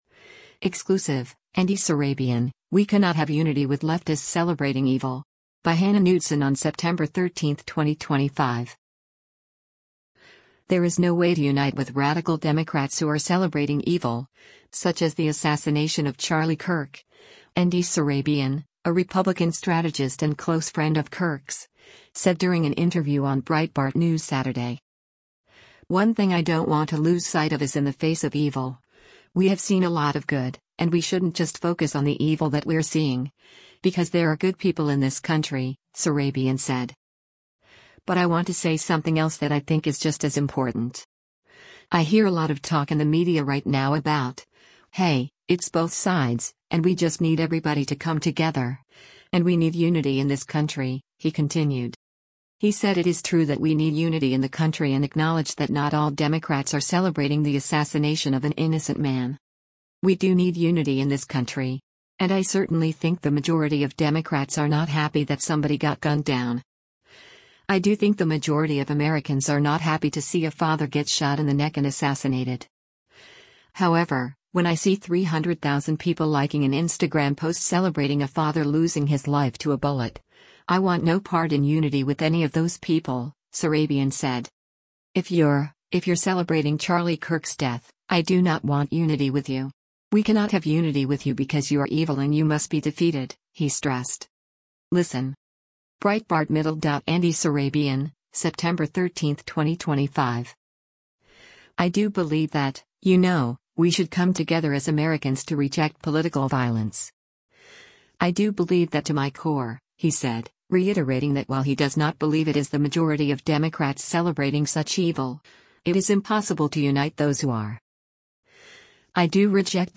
said during an interview on Breitbart News Saturday.